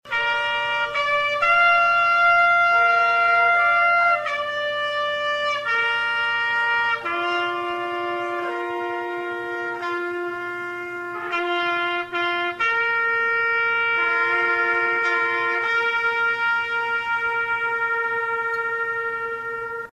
The ceremony once again concluded with the traditional gun salute and playing of Taps.